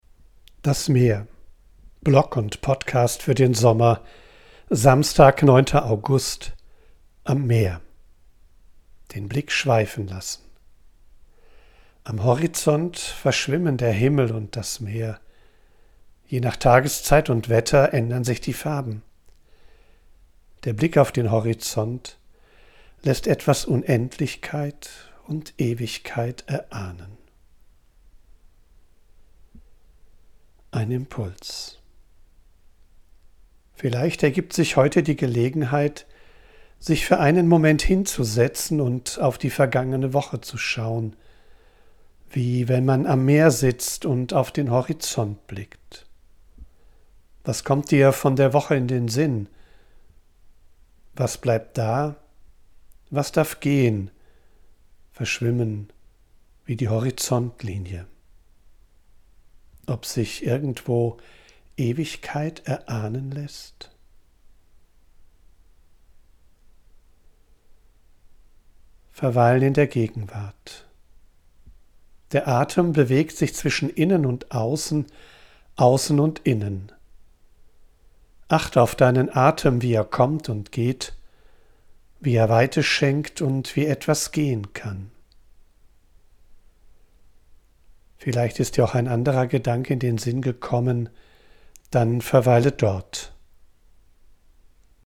Ich bin am Meer und sammle Eindrücke und Ideen.
von unterwegs aufnehme, ist die Audioqualität begrenzt. Dafür
mischt sie mitunter eine echte Möwe und Meeresrauschen in die